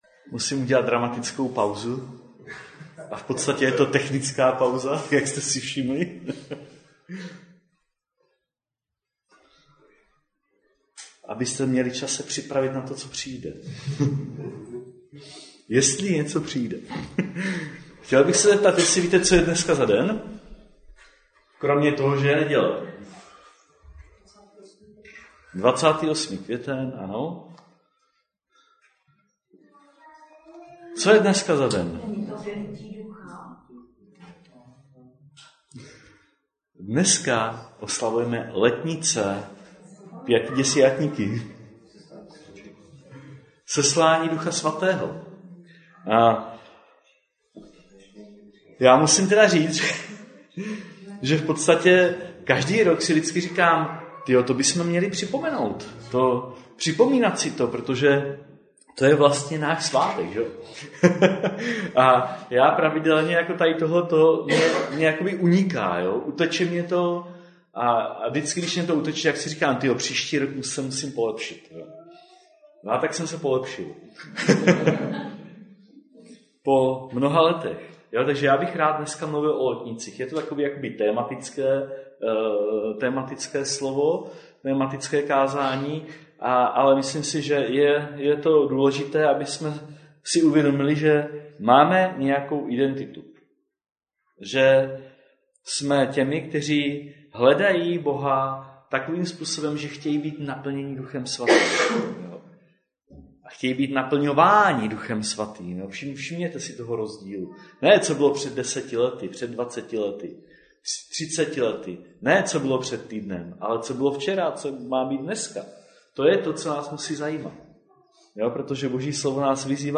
Kázání - archiv - 2023 | AC Velké Meziříčí, Velká Bíteš, Třebíč